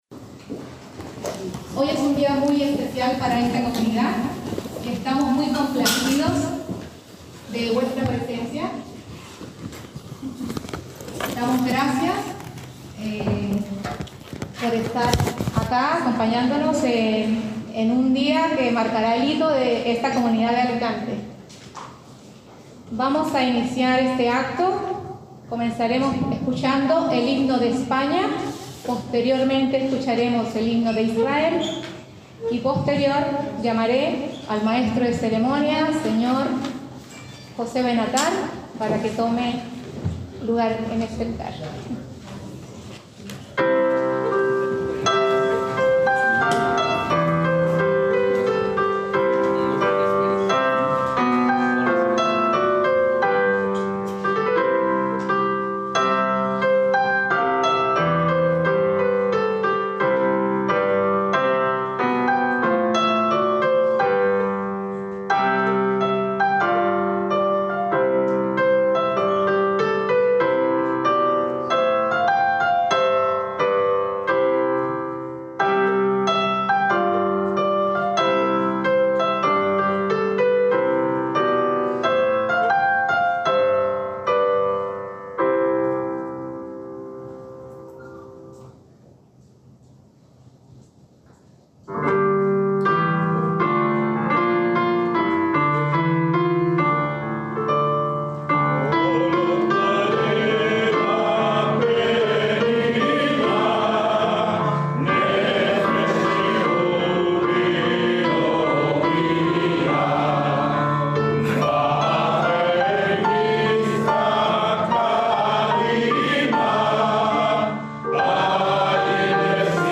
Inauguración de la nueva sinagoga de la kehilá de Alicante Centro